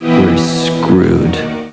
Dean's voice.